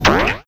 bubble4.wav